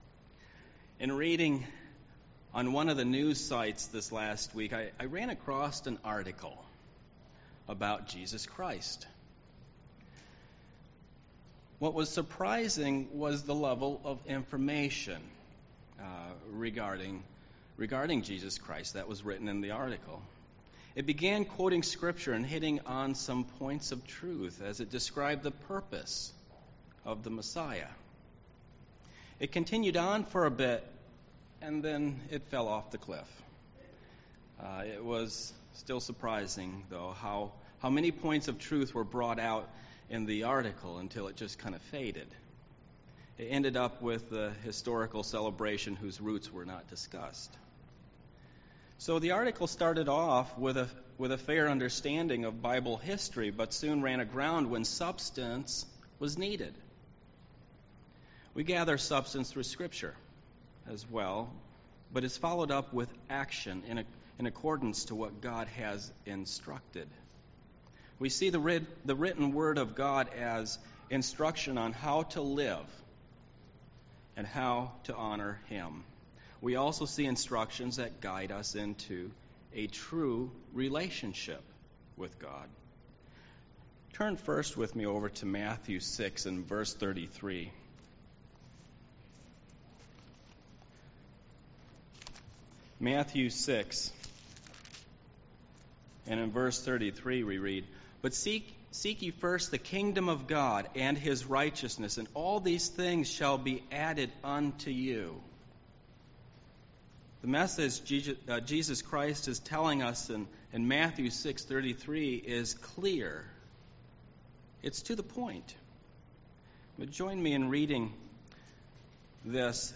Sermons
Given in Milwaukee, WI